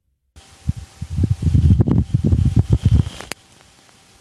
NASA's Perseverance rover has recorded the sound of lightning on Mars for the first time, capturing 55 instances of electrical activity through its microphone. Researchers believe the lightning is generated by electricity from dust storms on the planet rather than traditional weather patterns.
Blitze auf dem Mars recorded by Nasa.mp3